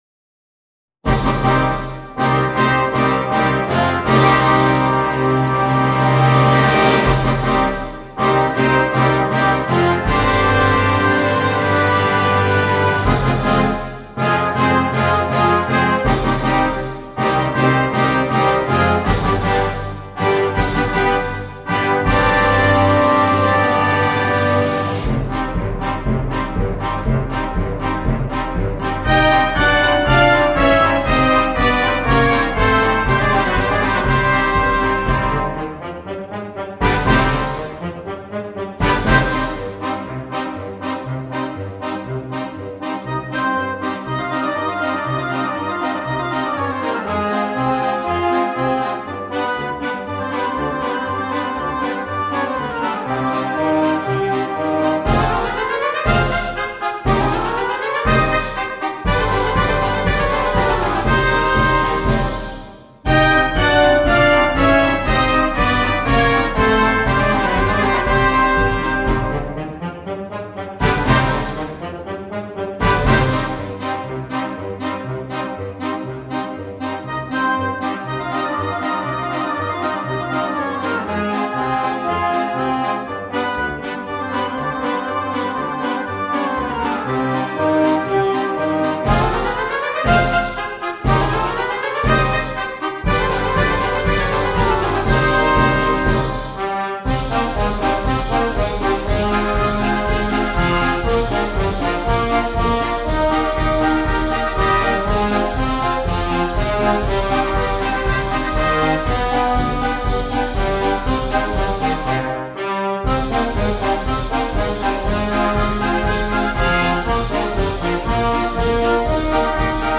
Per banda
MUSICA PER BANDA